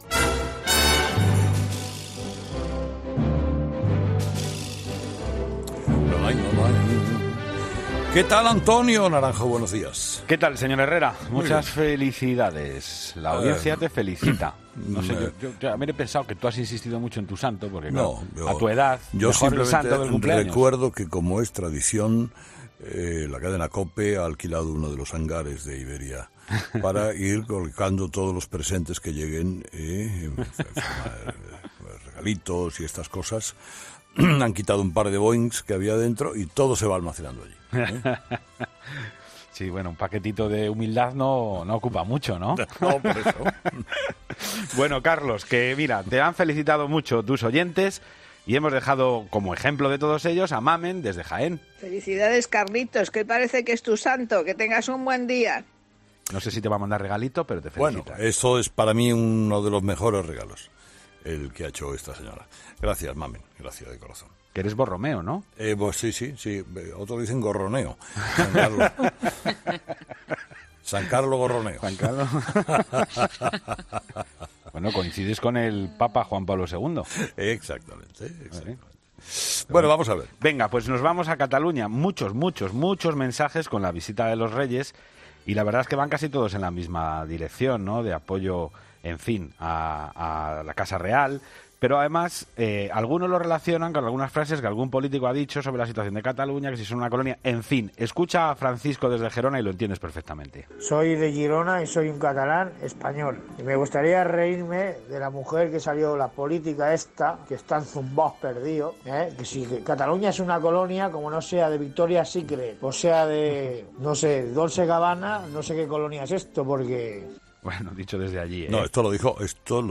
Avalancha de mensajes en el contestador de ‘Herrera en COPE’. Hoy muchas felicitaciones al líder por su santo y mensajes de apoyo desde Cataluña con la visita de los Reyes.